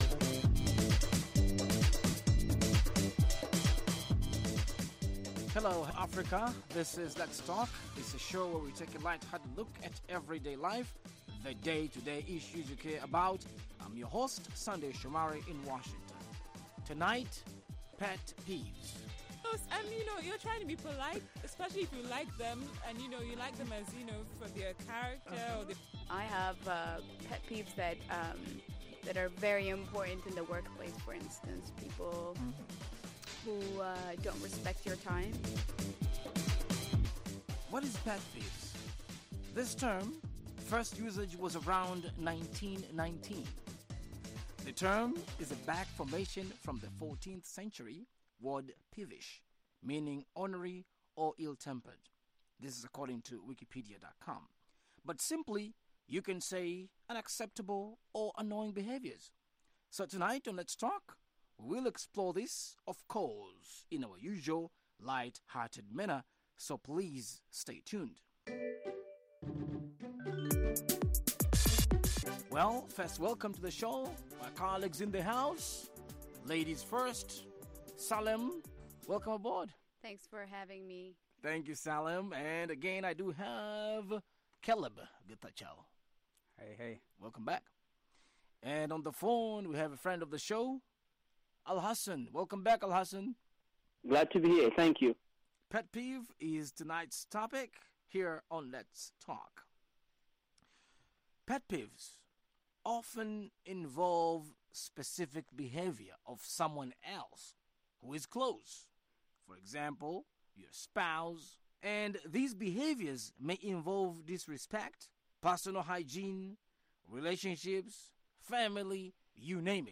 Let's Talk is an interactive discussion program about lifestyle issues.